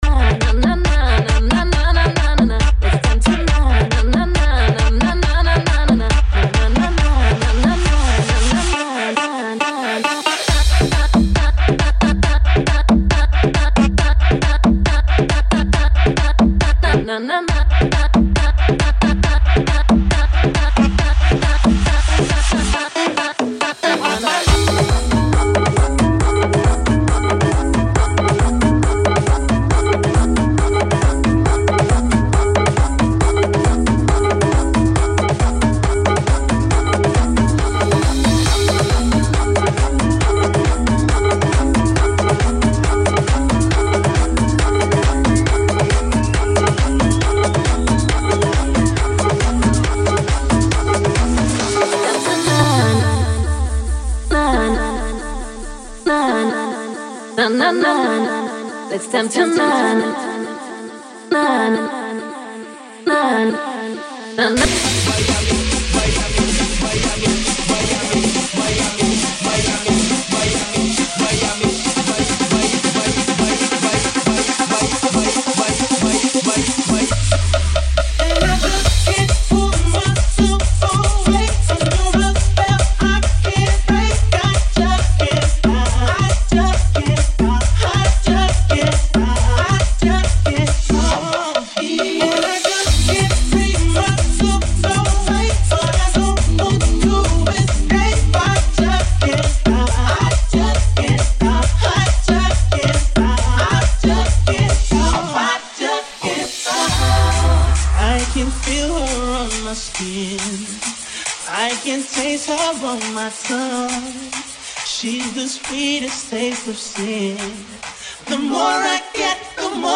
GENERO: REMIX